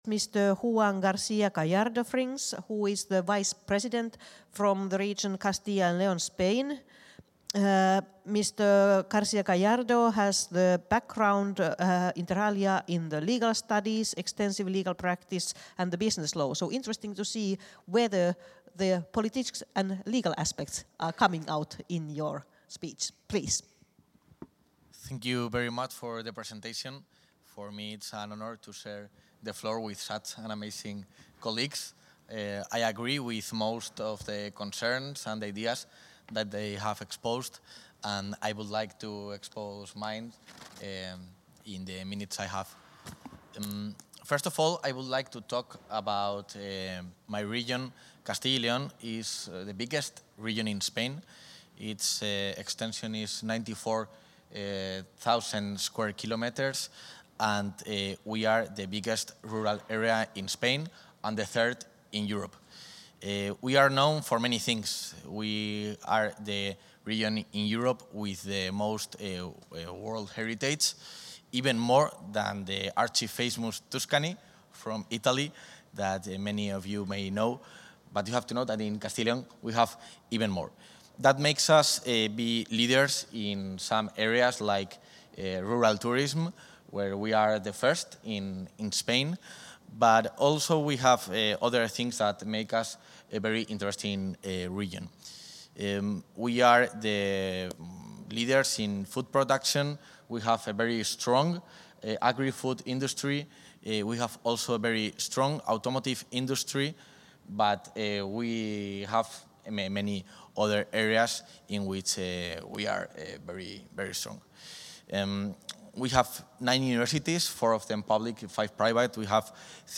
Intervención del vicepresidente.